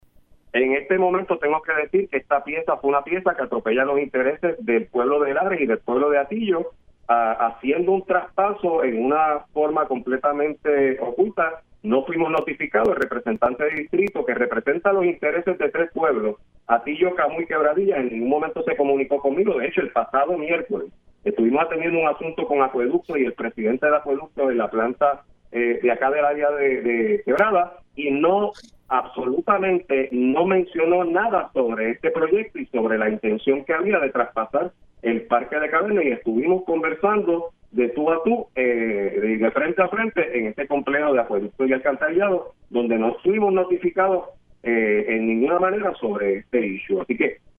312-CARLOS-ROMAN-ALC-HATILLO-MEDIDA-DE-LAS-CAVERNAS-AFECTA-DESARROLLO-ECONOMICO-DE-LARES-Y-HATILLO.mp3